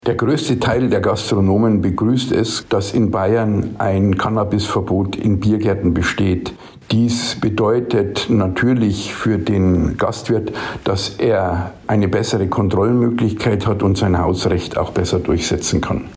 Interview: Das sagt die DEHOGA zum Cannabis-Verbot in der Gastronomie - PRIMATON